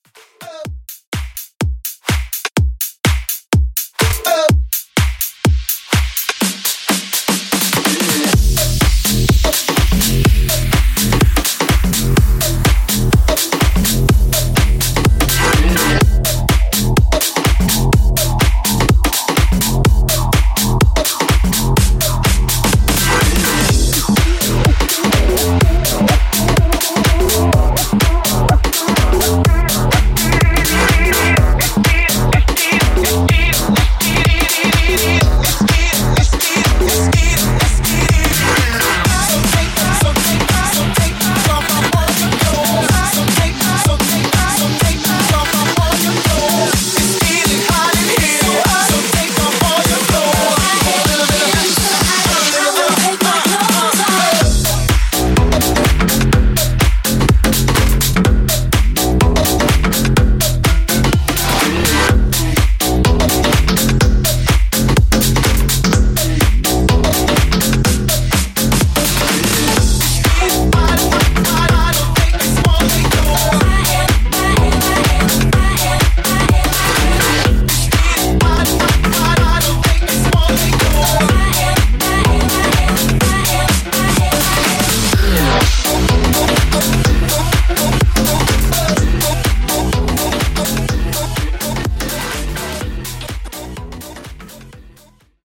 Bootleg House Repeat)Date Added